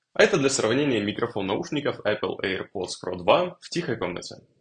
В тихих условиях: